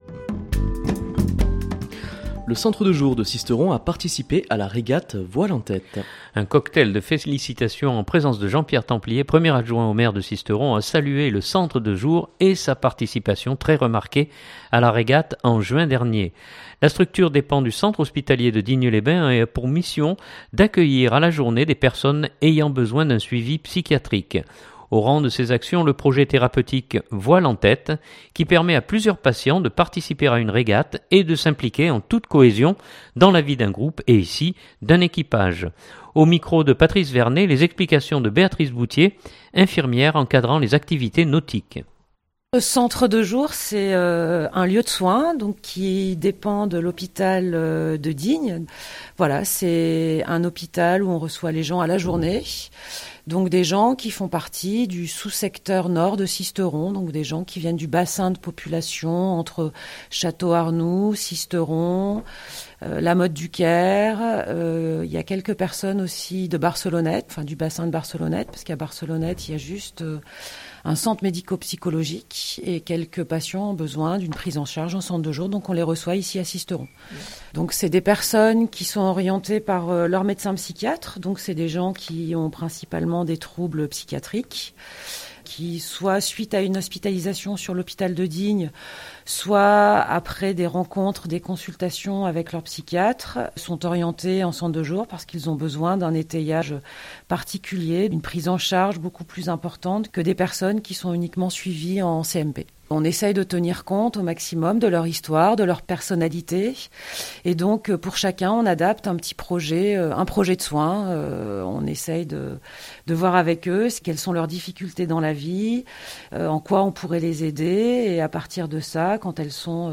Un cocktail de félicitation en présence de Jean-Pierre Templier, 1° Adjoint au Maire de Sisteron a salué le Centre de jour et sa participation très remarquée à la régate en juin dernier.